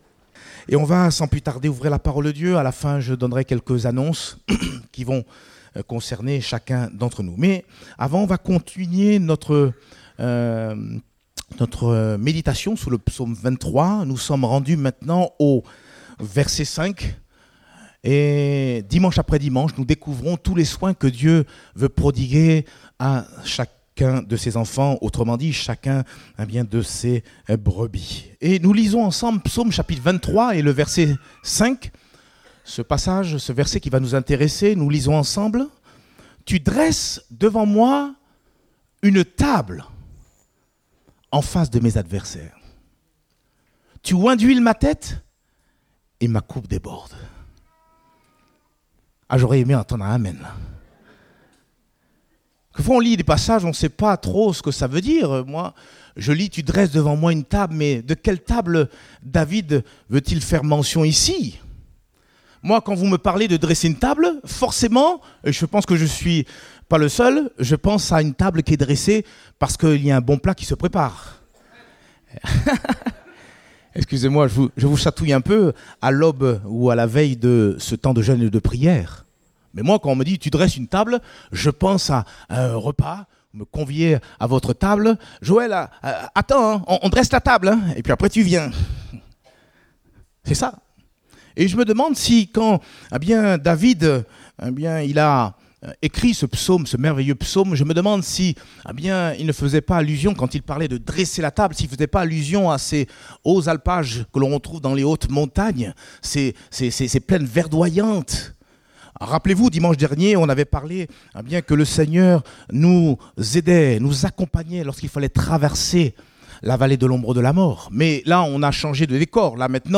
Date : 22 septembre 2019 (Culte Dominical)